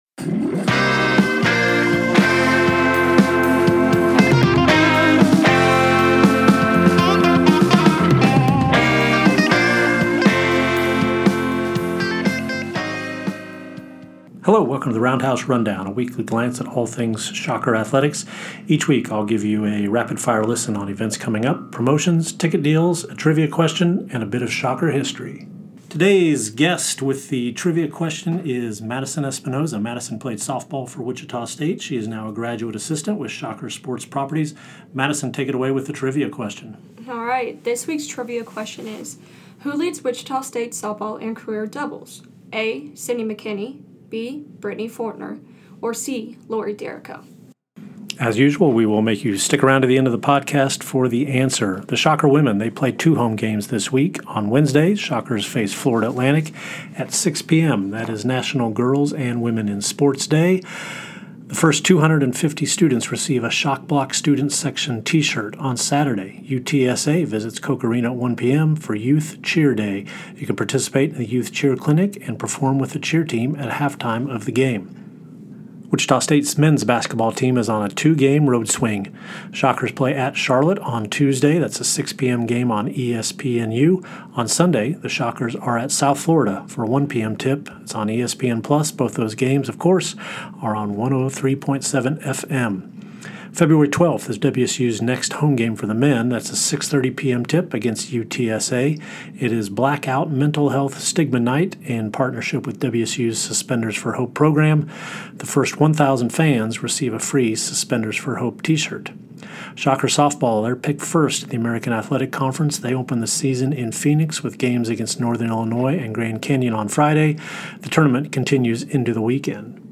our guest with the trivia question. WSU’s men’s basketball is on the road for two games this week.